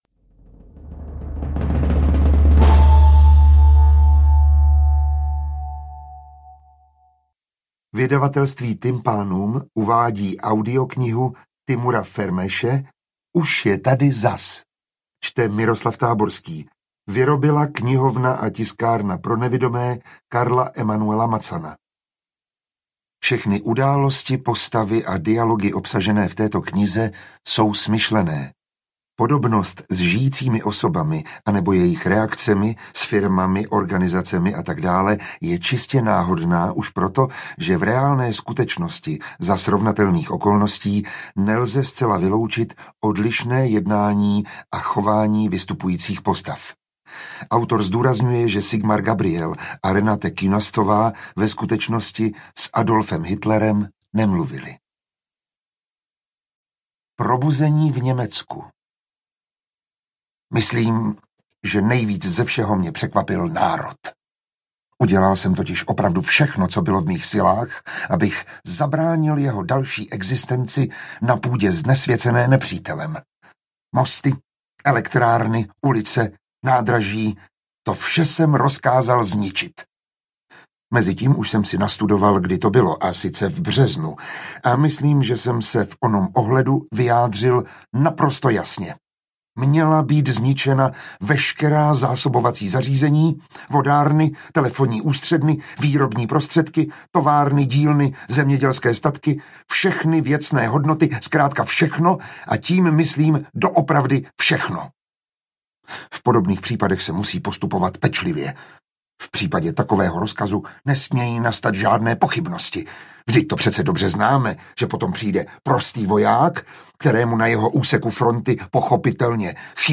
Interpret:  Miroslav Táborský